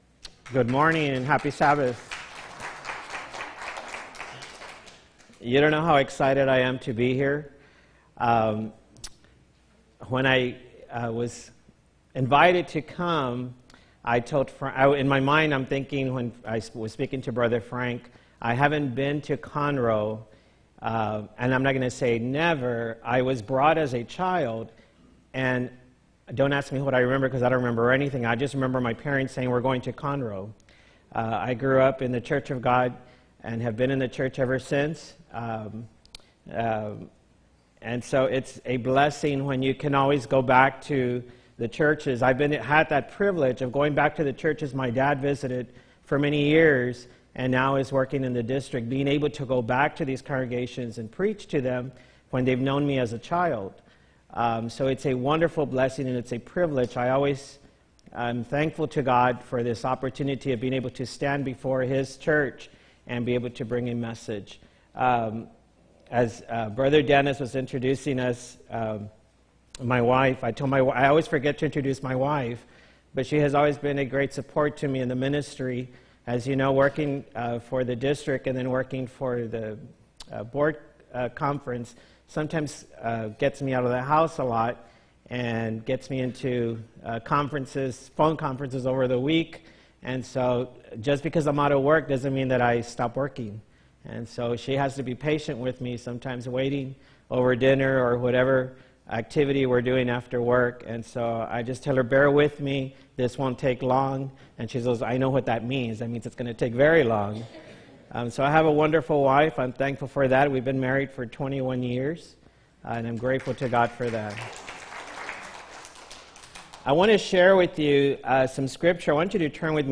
4-7-18 sermon